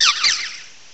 cry_not_cutiefly.aif